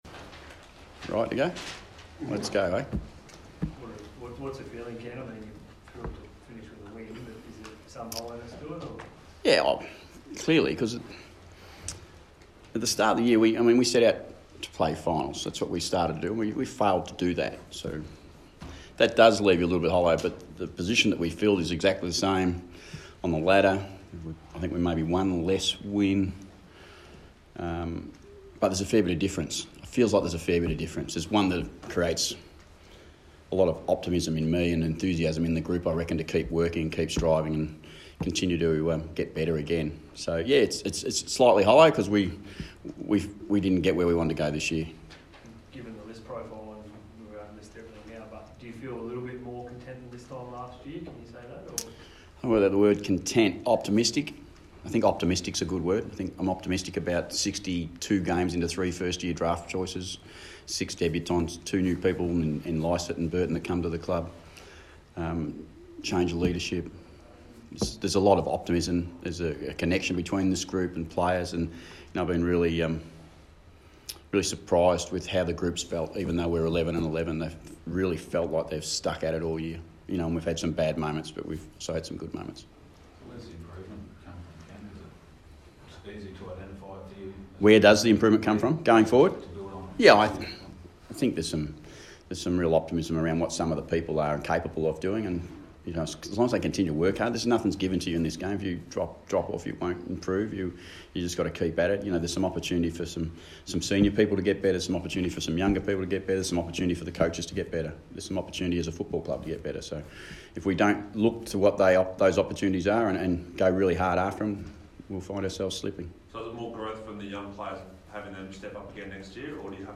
Ken Hinkley press conference - Sunday 25 August, 2019